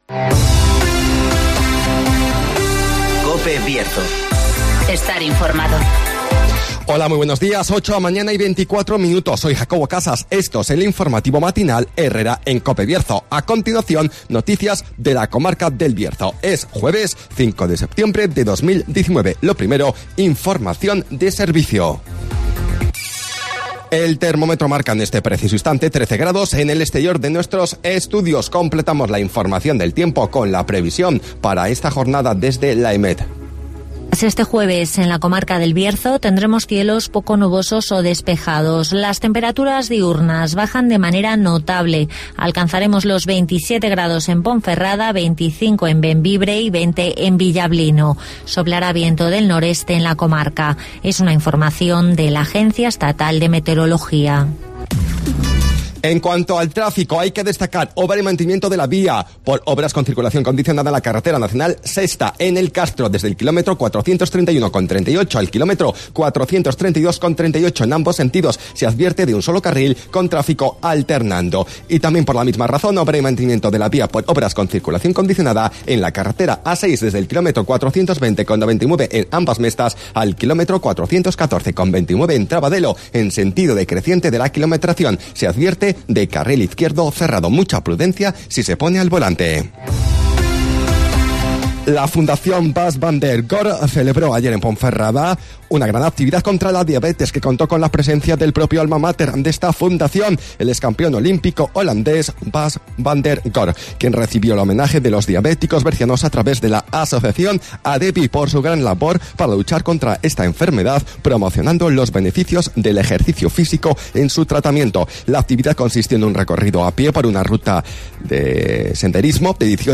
INFORMATIVOS
-Conocemos las noticias de las últimas horas de nuestra comarca, con las voces de los protagonistas